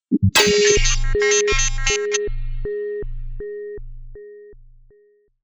UI_SFX_Pack_61_12.wav